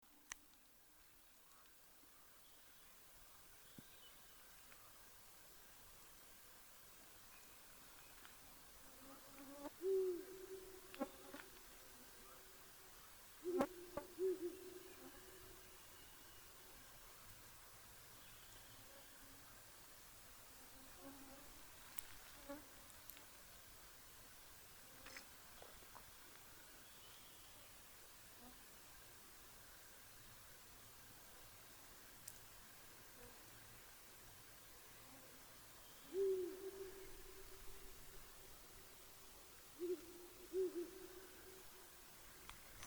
Ural Owl, Strix uralensis